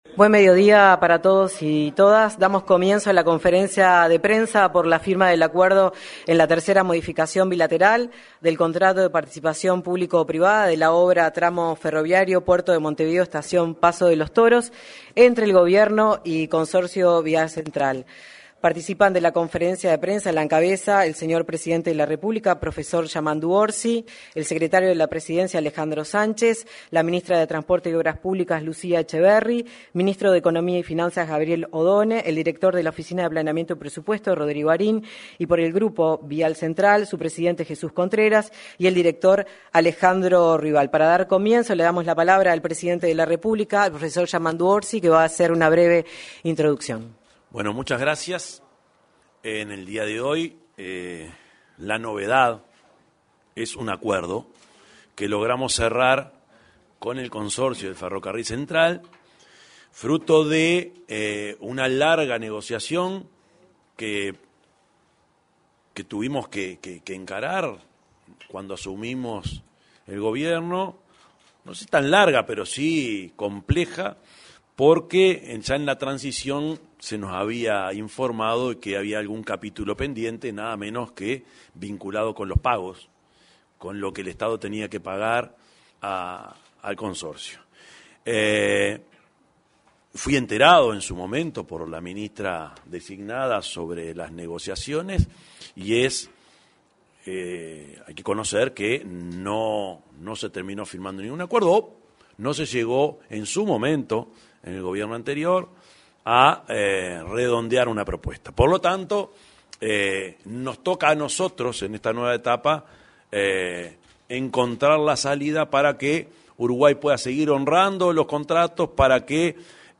En una conferencia de prensa acerca del acuerdo entre el Gobierno y el Grupo Vía Central, se expresaron el presidente de la República, Yamandú Orsi;